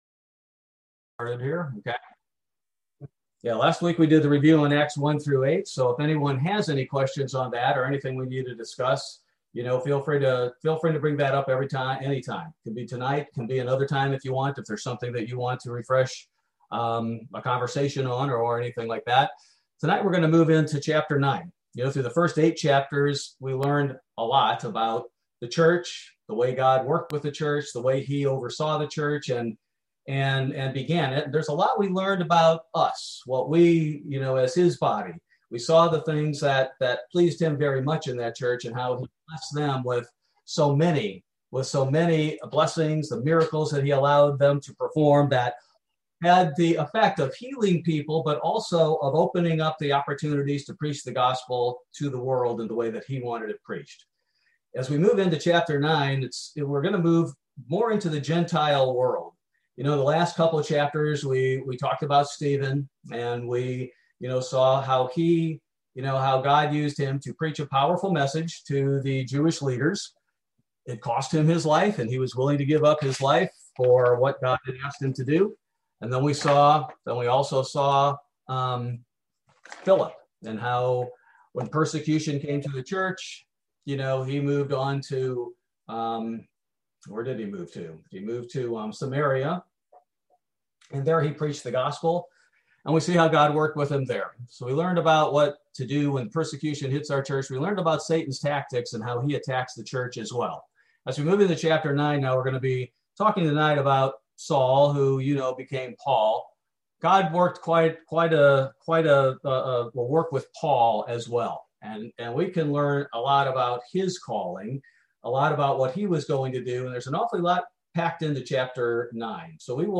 Bible Study: July 14, 2021